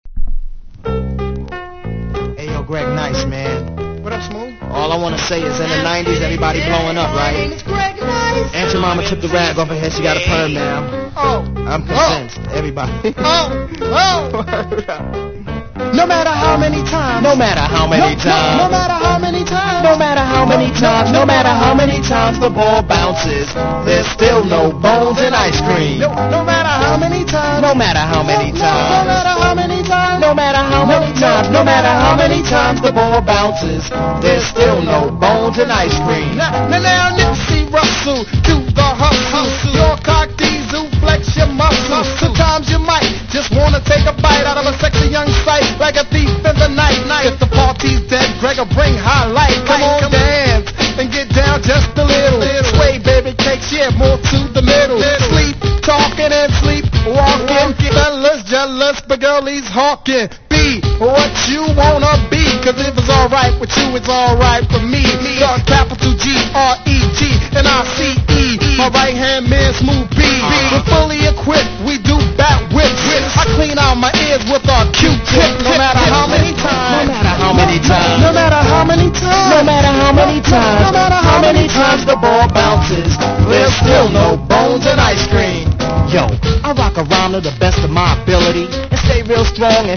キャッチーなトラックでお馴染みの定番曲！